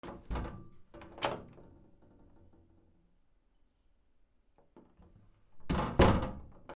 / K｜フォーリー(開閉) / K05 ｜ドア(扉)
室内 ドアの開け閉め